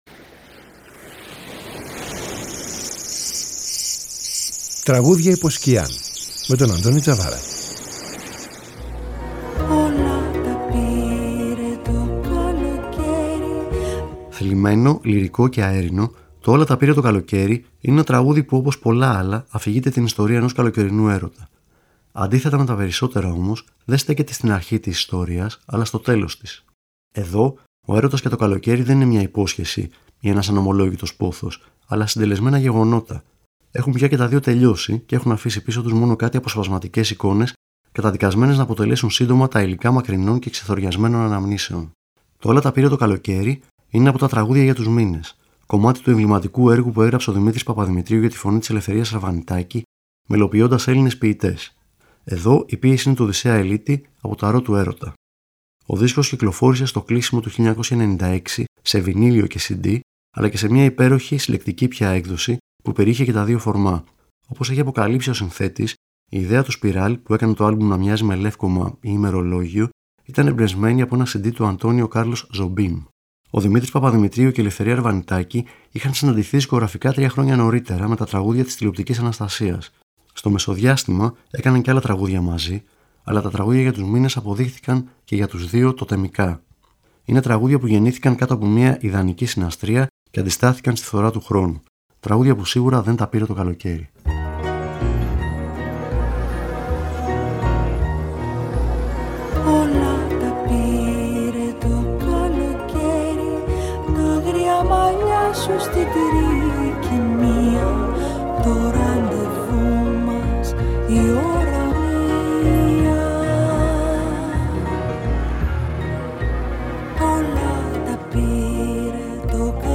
Θλιμμένο, λυρικό και αέρινο